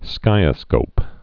(skīə-skōp)